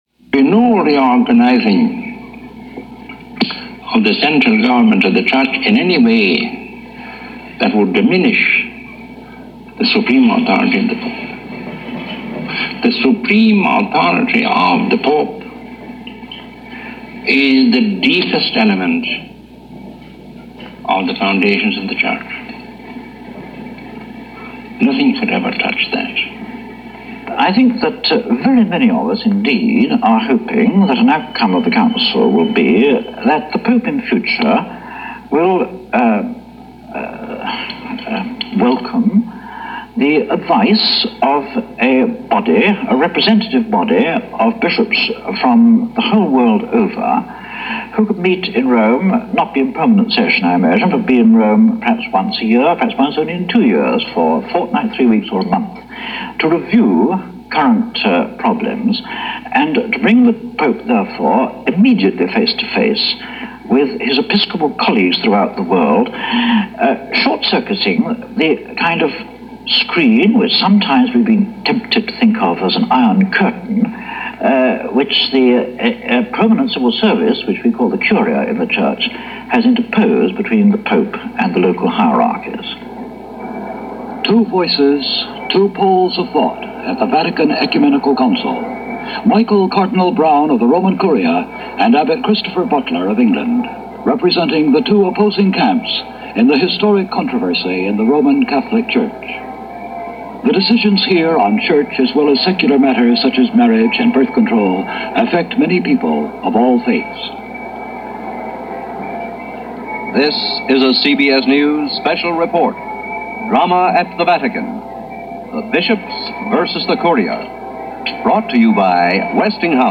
This documentary, hosted by CBS Rome correspondent Winston Burdett, discusses the factions involved in what was pretty intense in-fighting.